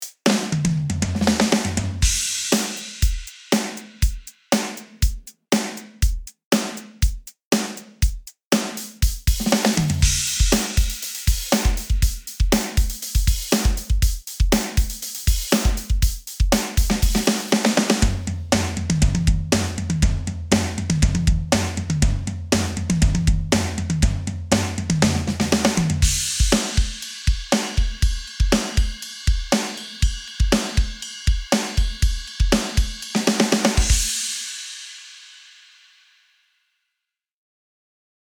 収録されているドラムパターンを適当に組み合わせたのがこちら。
クセがなく、非常にシンプルで使いやすいものが揃っていると思います。
上のドラムパターンはすべて NORMAL です。
MTPDK_demo_normal.mp3